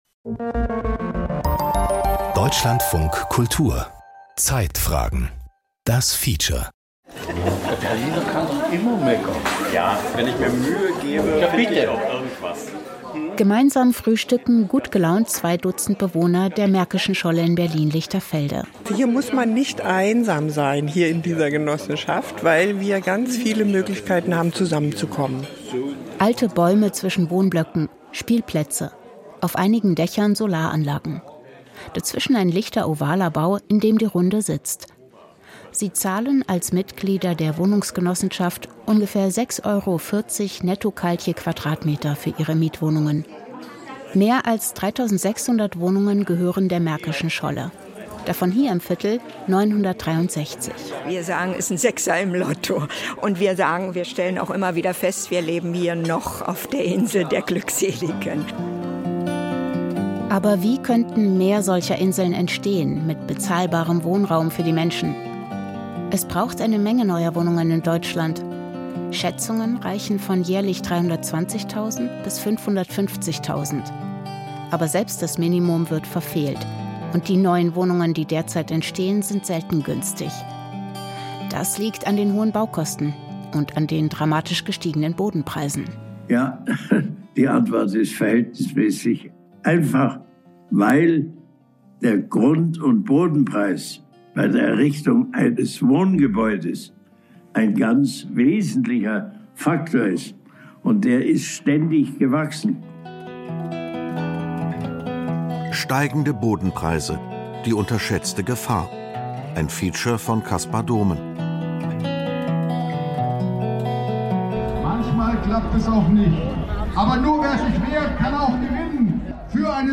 Feature Podcast abonnieren Podcast hören Podcast Zeitfragen-Feature Unsere Welt ist komplex, die Informationsflut überwältigend.